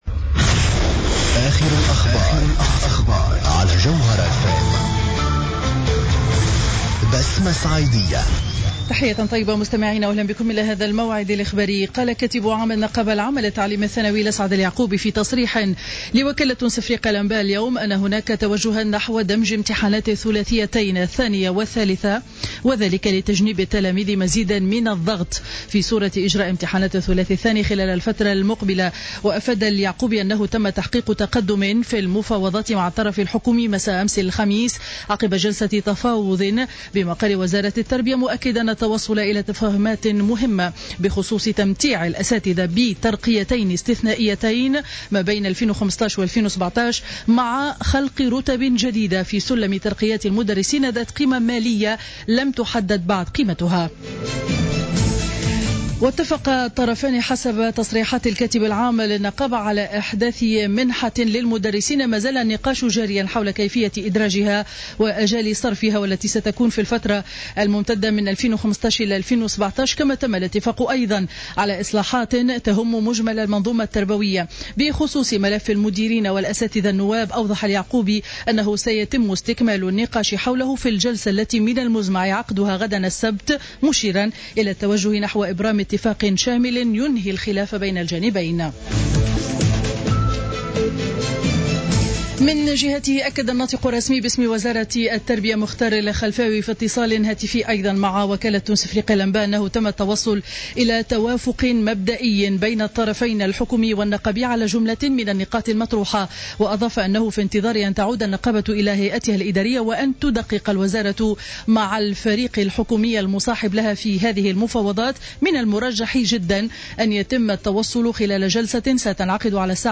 نشرة أخبار منتصف النهار ليوم الجمعة 03 أفريل 2015